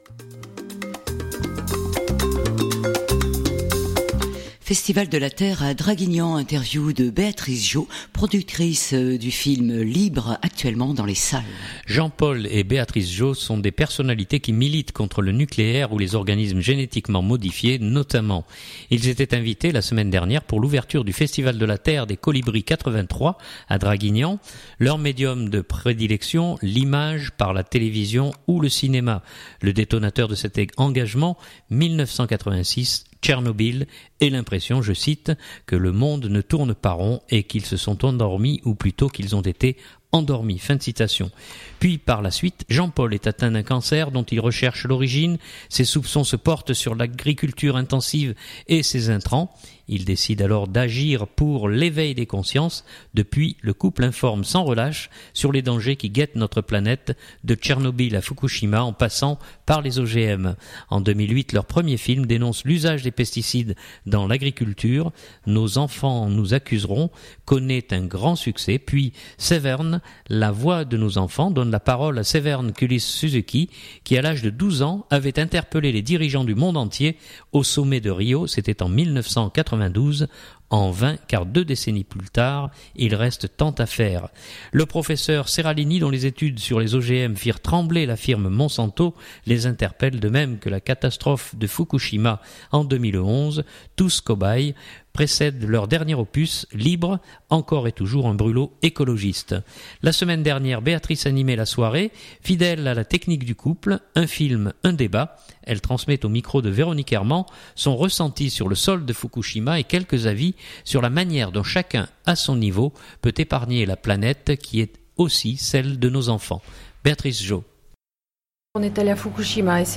Festival de la Terre - Colibris 83 - Interview